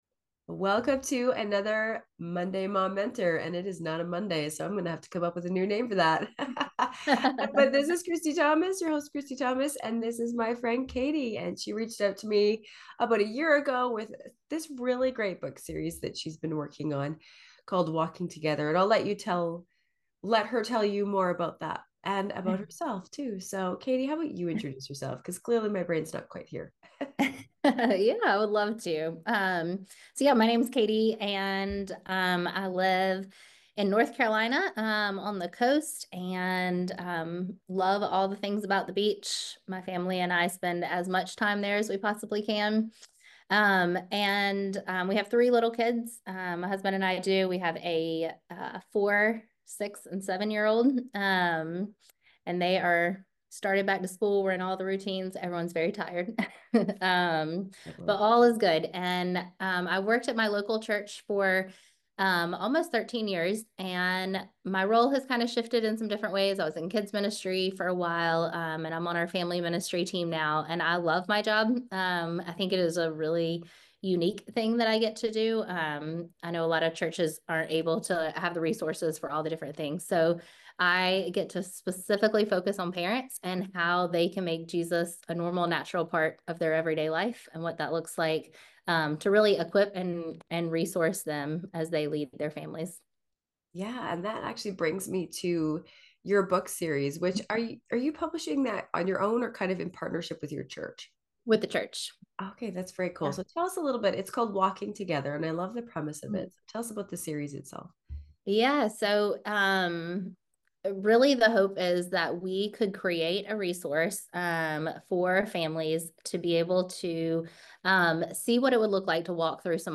heartwarming conversation